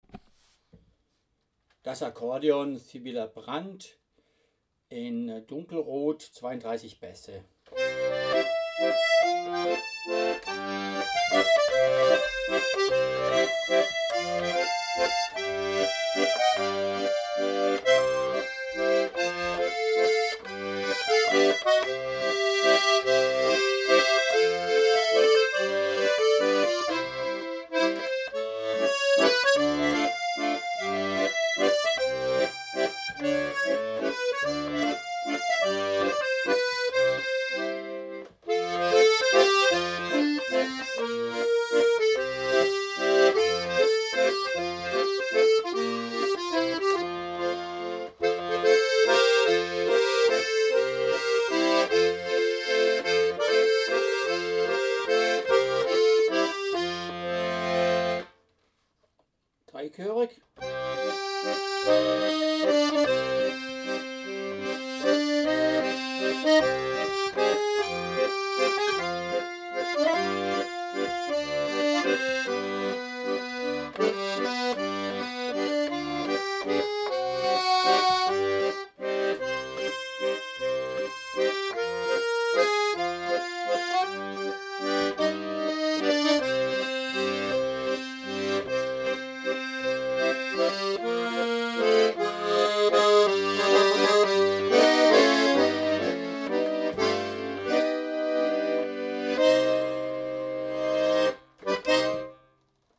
PIEMONTE (gebraucht) kleines italienisches Pianoakkordeon ultra-leicht Typ: 24 Bässe, Holzkerntasten, Grundbass tipo-a-mano Bemerkungen: Ein kleiner Italiener aus Vercelli/ Piemont, aber OHO!!
erstaunlich klangvoll, kräftiges Tremolo
Frequenz / Tremolofrequenz von a=440/sec: 444 Hz / 20 cent